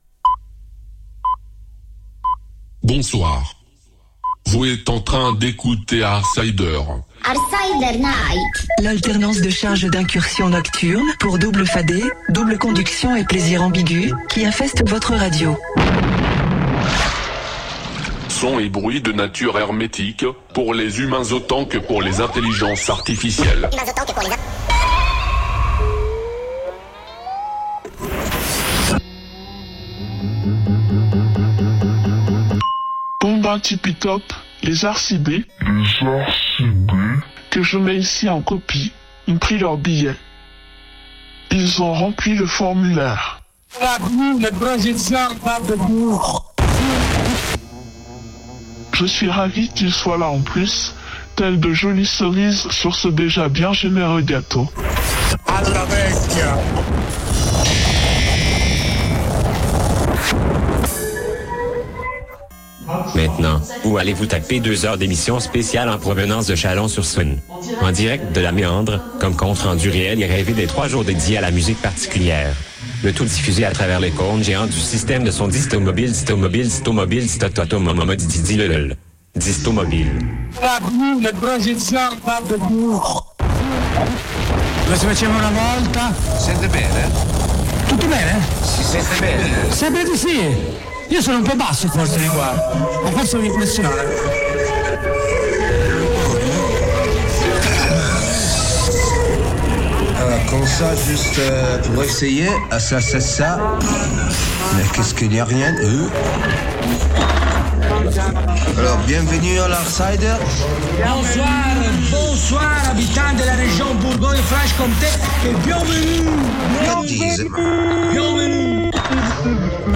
Algorithme de mixage : entropie maximale.